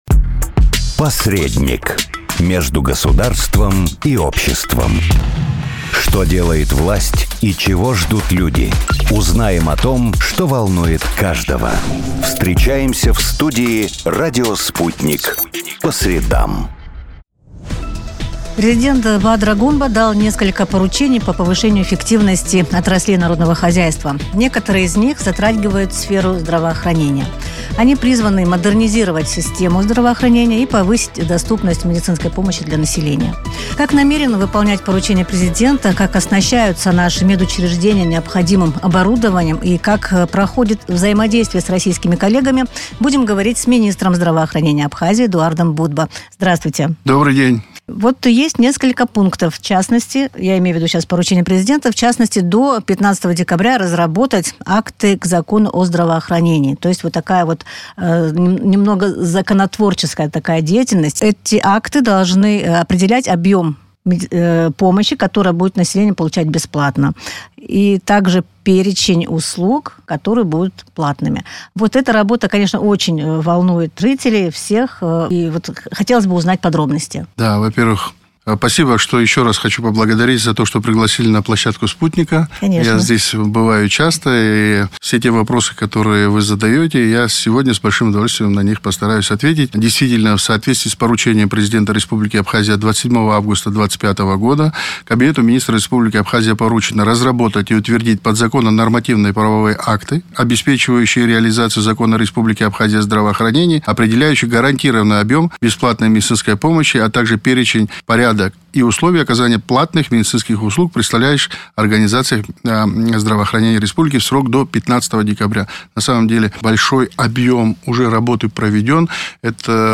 Поручения президента Минздраву: интервью с главой ведомства